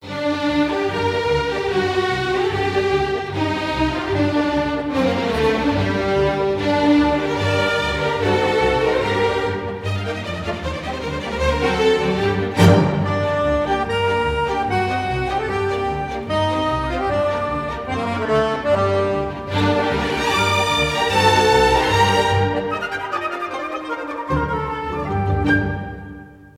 инструментальные , оркестр , классические
без слов